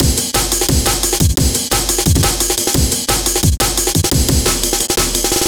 cw_amen17_175.wav